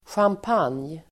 Ladda ner uttalet
Uttal: [sjamp'an:j]